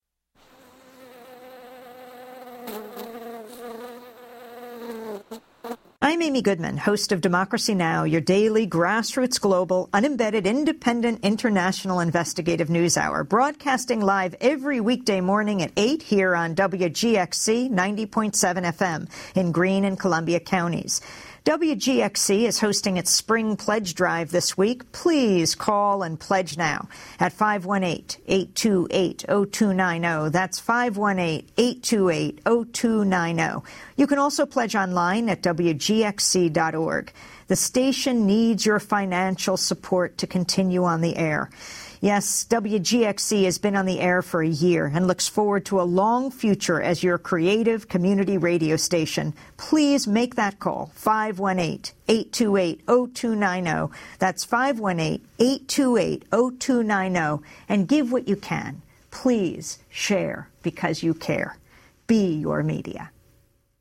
Recorded by Amy Goodman. With five seconds of bees at beginning.